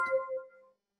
Phone Notification
A pleasant, short phone notification chime with a bright, modern tone
phone-notification.mp3